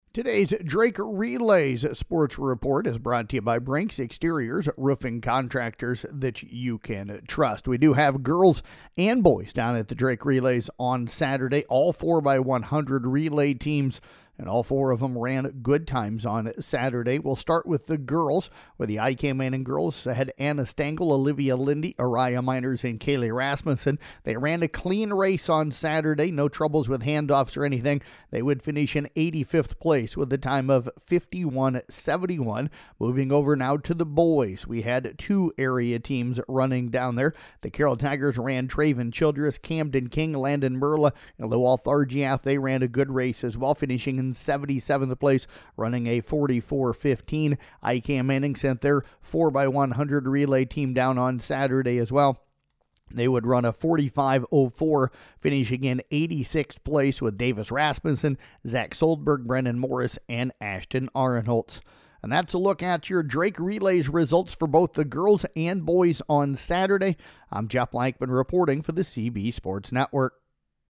Below is an audio recap from the Drake Relays for Saturday, April 25th